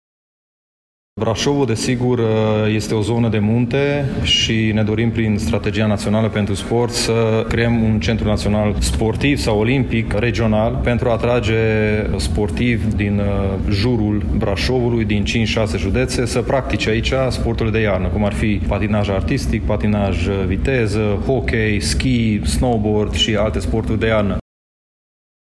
Prezent la Brașov unde a asistat la Finala Cupei României la Volei Masculin, ministrul Sportului, Eduard Novak, a punctat elementele de strategie în dezvoltarea sportului național marcând rolul pe care îl va avea Brașovul.
Specificul pentru sporturile de iarnă este un atu pe care actualul ministru dorește să îl exploateze în viitor pentru zona din centrul țării, spune ministrul Eduard Novak: